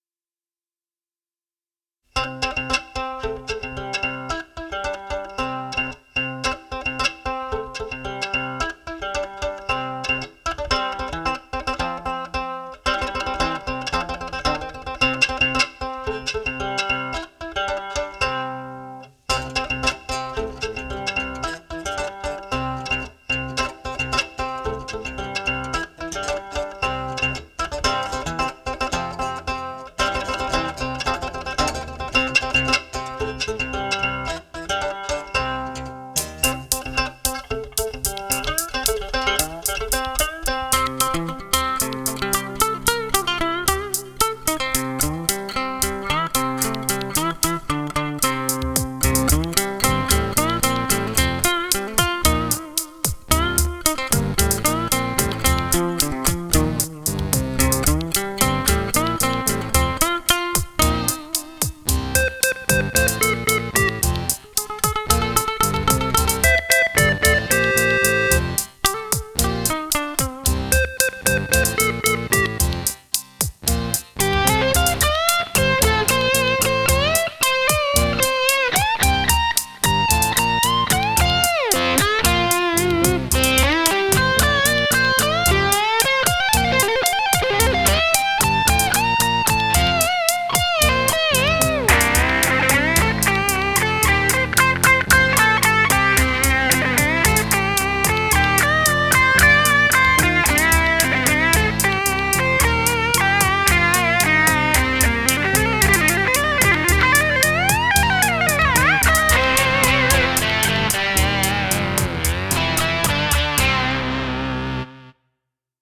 *-1-* Old McDonald had a farm ... and a cookie tin banjo !
OldMacDonald had a farm(cookie tin banjo).mp3